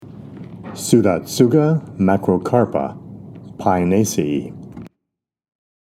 Pronunciation:
Pseu-dot-sù-ga ma-cro-cár-pa